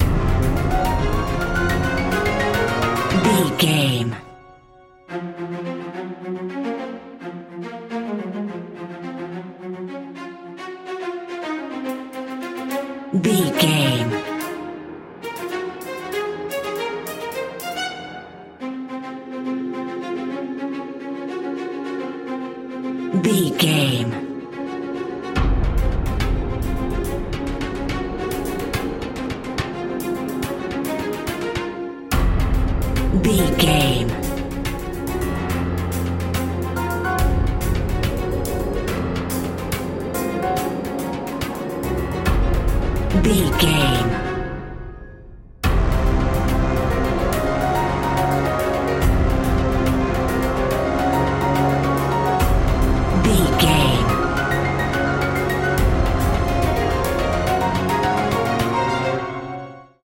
Epic / Action
Fast paced
Phrygian
chaotic
dramatic
intense
horns
orchestra
strings
percussion
brass